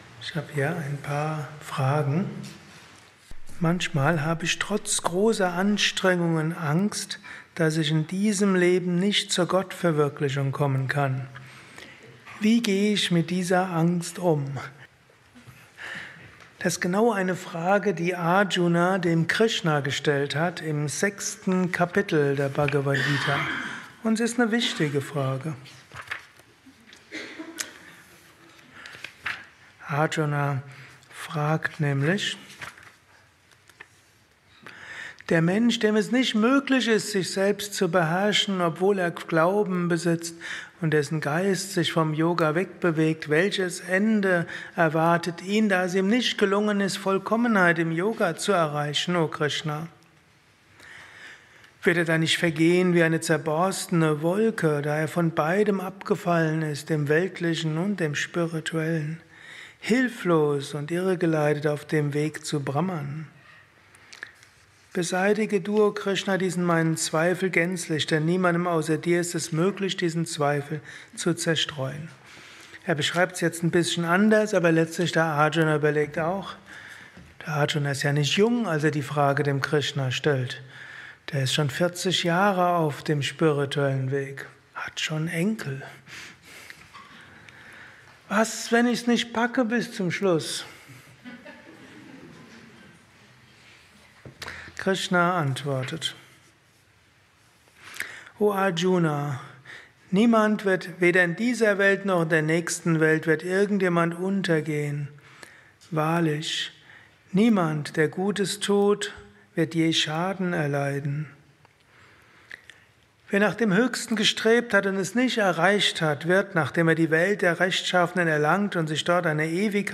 Höre einen kurzen Beitrag über die Angst, nicht die Gottverwirklichung zu erreichen. Dies ist ein kurzer Vortrag als Inspiration für den heutigen Tag
eine Aufnahme während eines Satsangs gehalten nach einer Meditation im Yoga Vidya Ashram Bad Meinberg.